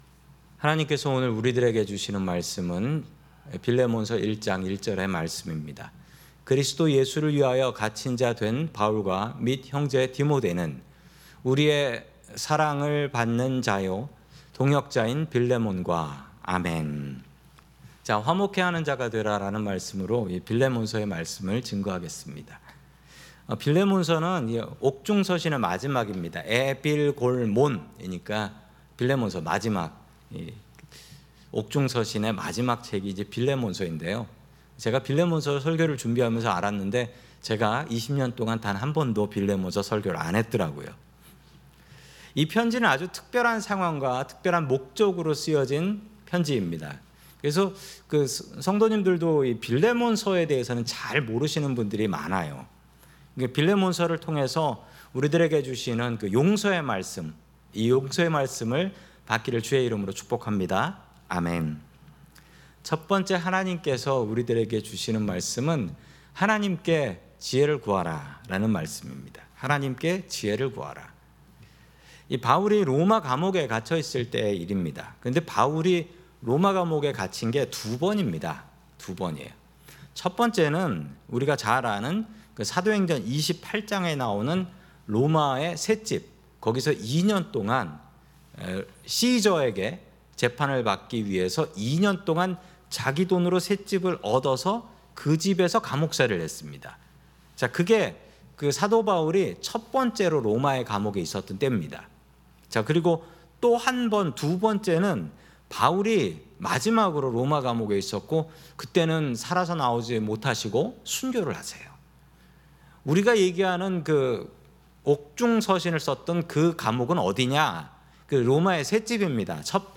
샌프란시스코 은혜장로교회 설교방송